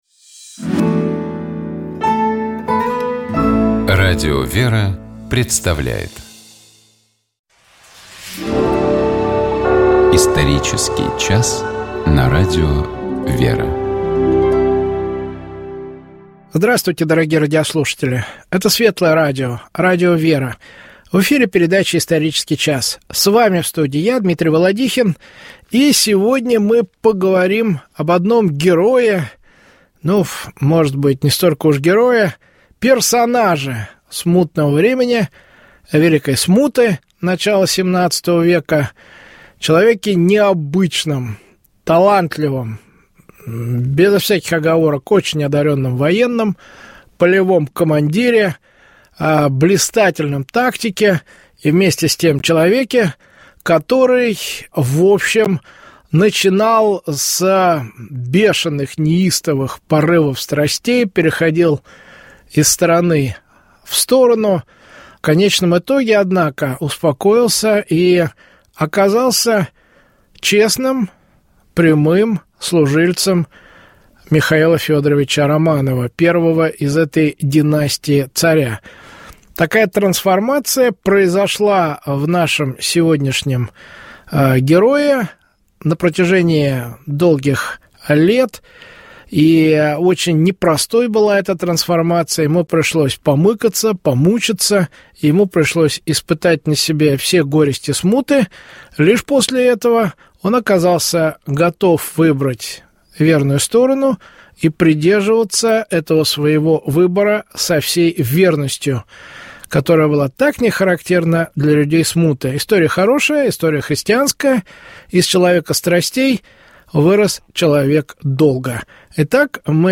беседует со своими гостями в программе «Исторический час».